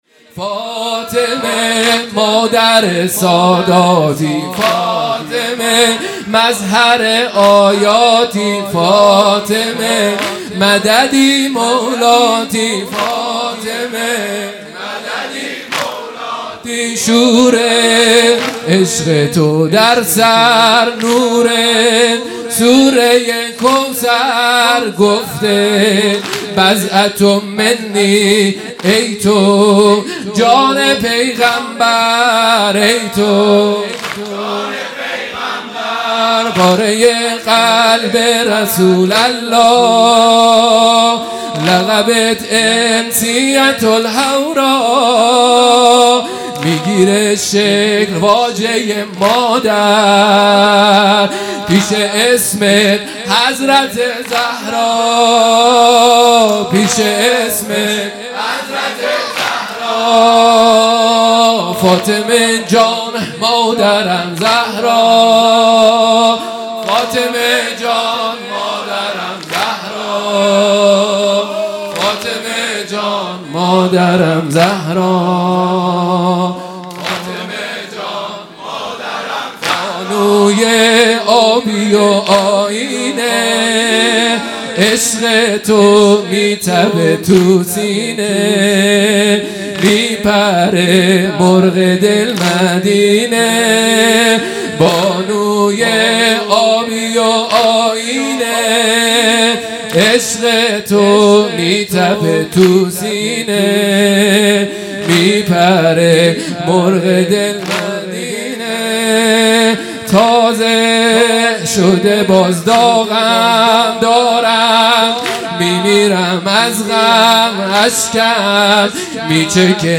هیئت مکتب الزهرا(س)دارالعباده یزد
0 0 واحد تند | فاطمه مادر ساداتی مداح
فاطمیه دوم_شب هفتم